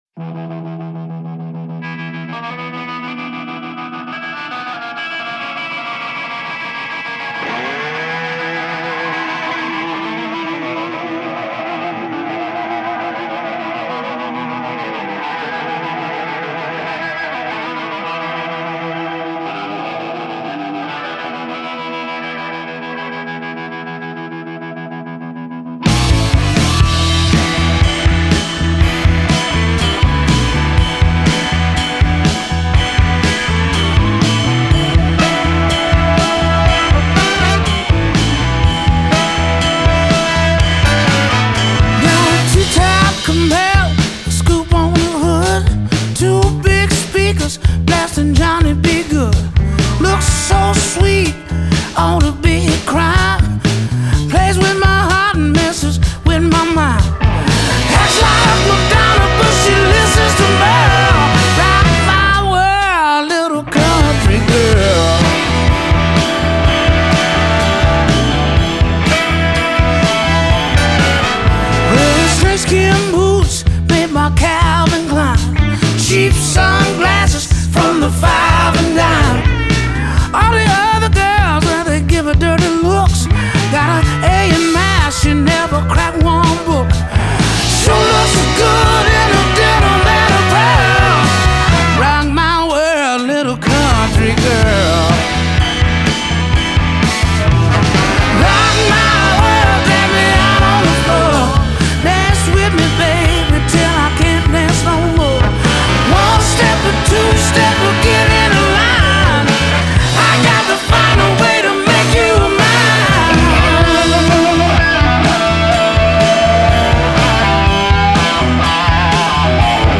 Трек размещён в разделе Зарубежная музыка / Кантри.